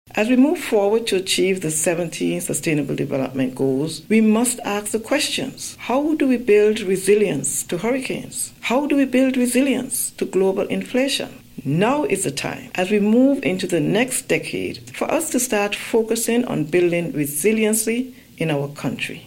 On the occasion of the nation’s 40th Independence Celebration, the Prime Minister and Premier as well as political figures in the federation addressed citizens and residents on September 19th.
Voicing her views was Member of the Opposition in the Nevis Island Assembly and Parliamentary Representative of Nevis #4 (St. James’ Parish), Hon. Dr. Janice Daniel Hodge: